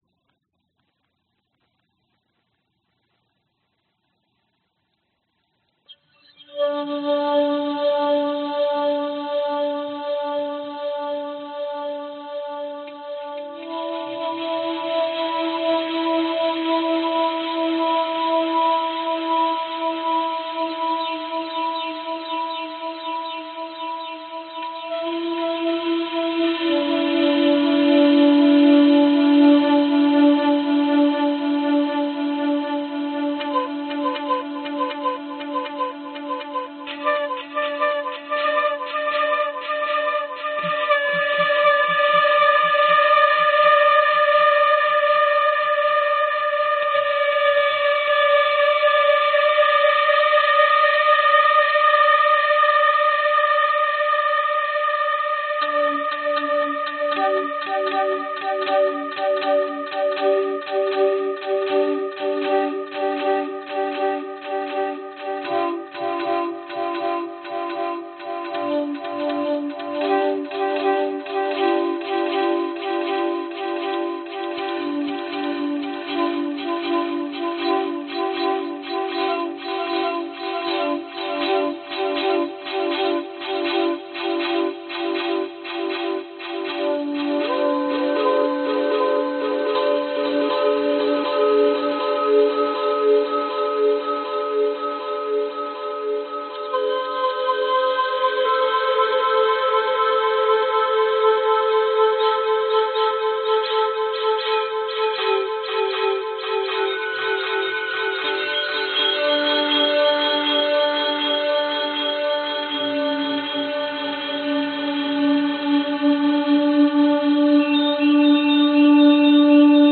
描述：在我的尺八上用夸张的混响和平移进行自由演奏